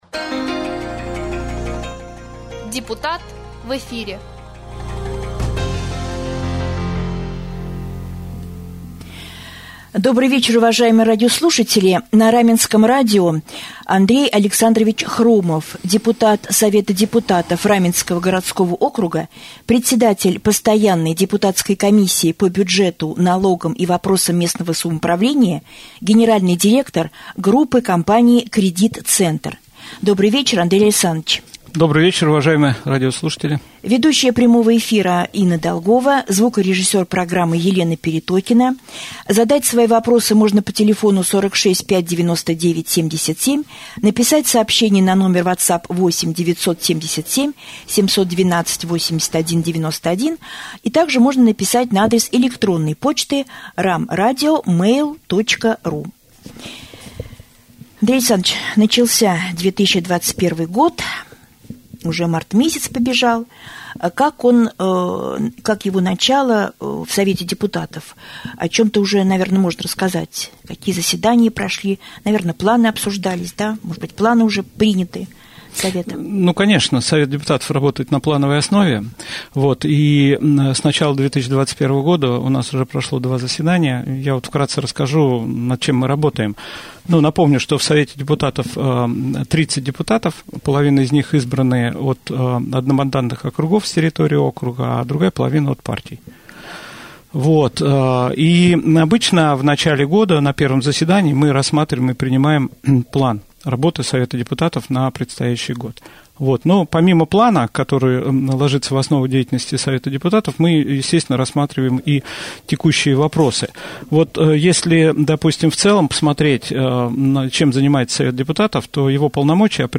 Герой рубрики «Депутат в эфире» Андрей Александрович Хромов – депутат Совета Депутатов Раменского городского округа и председатель постоянной депутатской комиссии по бюджету, налогам и вопросам местного самоуправления.
prjamoj-jefir.mp3